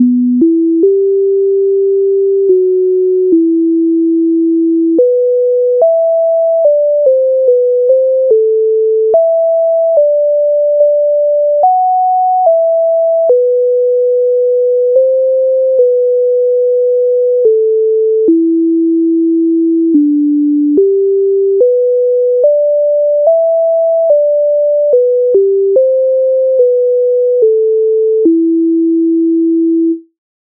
MIDI файл завантажено в тональності e-moll
Ішлв козак долиною Українська народна пісня з обробок Леонтовича с,128
Ukrainska_narodna_pisnia_Ishlv_kozak_dolynoyu.mp3